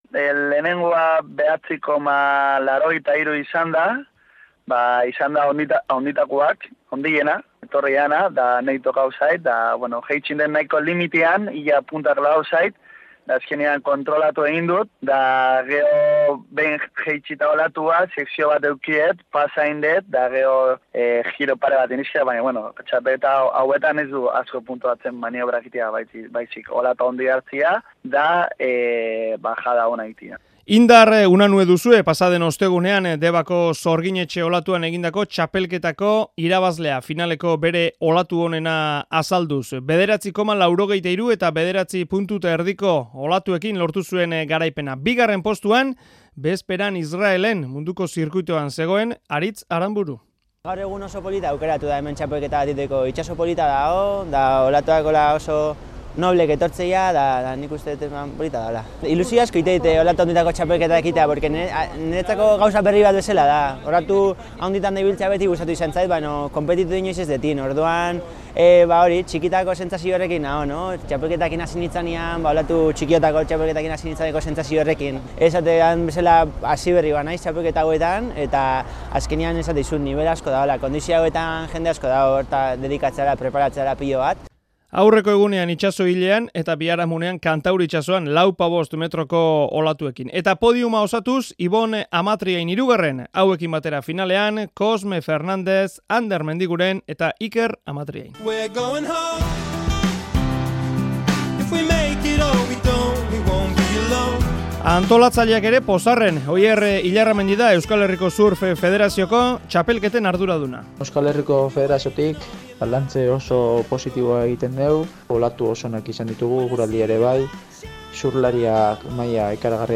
Deban jokatutako olatu handien txapelketako erreportajea